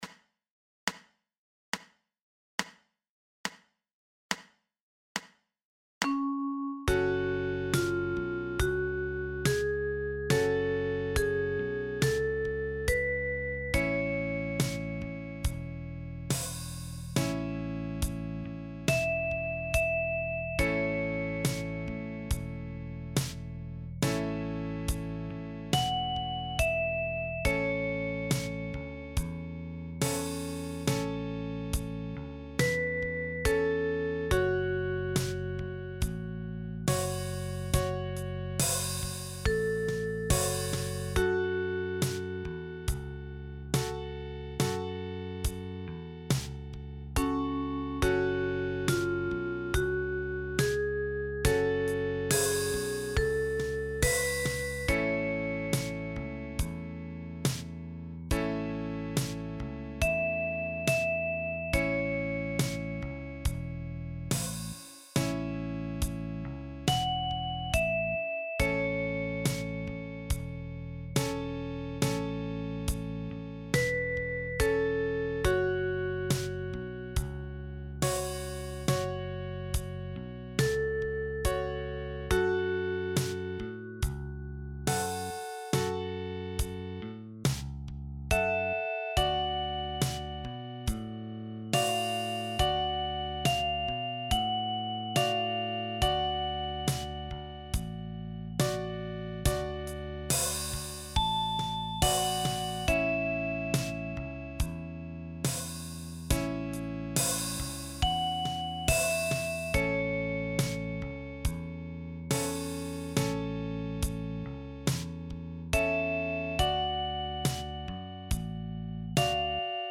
Für Sopran- oder Tenorblockflöte in barocker Griffweise.